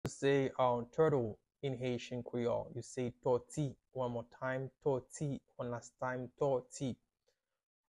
“Turtle” means “Tòti” in Haitian Creole – “Tòti” pronunciation by a native Haitian teacher
“Tòti” Pronunciation in Haitian Creole by a native Haitian can be heard in the audio here or in the video below:
How-to-say-Turtle-in-Haitian-Creole-–-Toti-pronunciation-by-a-native-Haitian-teacher.mp3